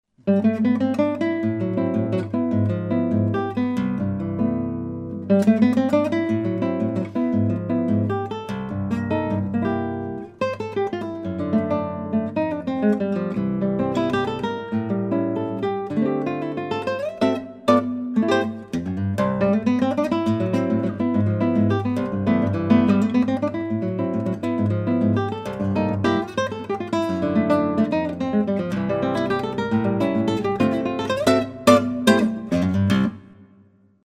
7 String Brazilian guitar based on a Miguel Rodriguez style
European Spruce soundboard, Brazilian Rosewood back & sides-